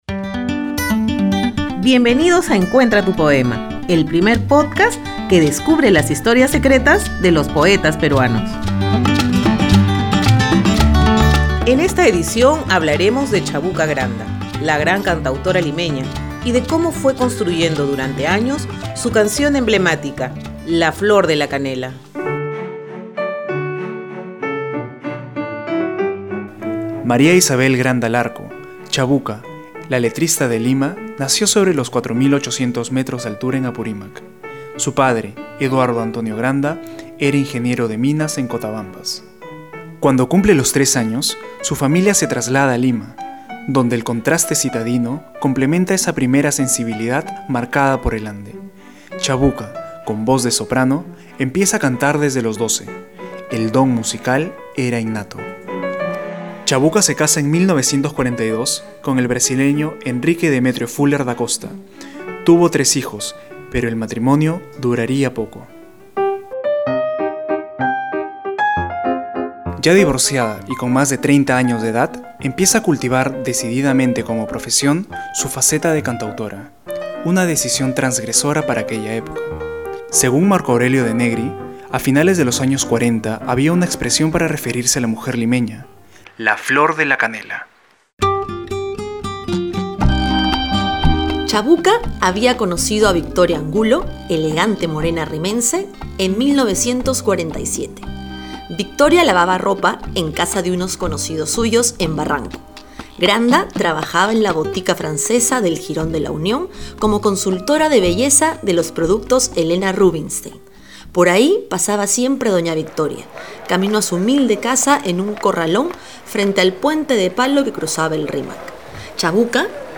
En Encuentra tu poema, la actriz Emilia Drago da lectura a una de sus composiciones más intensas, “José Antonio”.